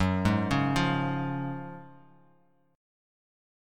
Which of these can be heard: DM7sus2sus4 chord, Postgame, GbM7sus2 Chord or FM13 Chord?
GbM7sus2 Chord